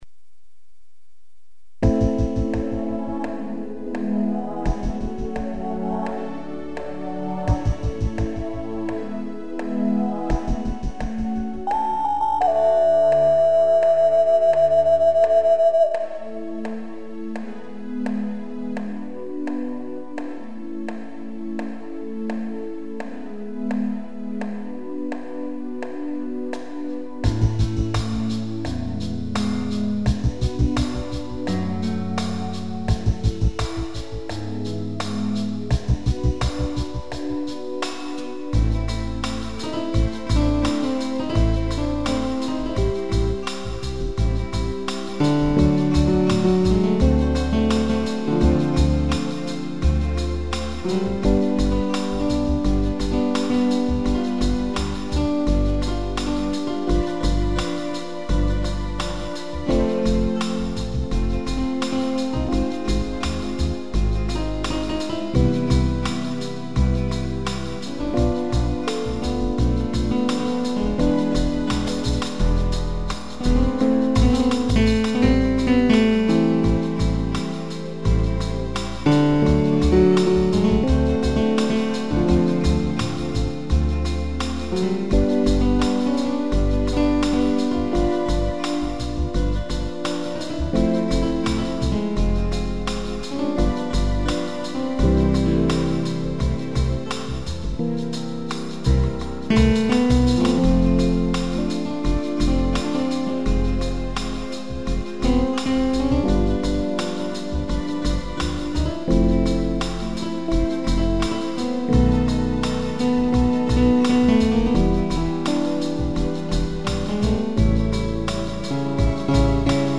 instrumental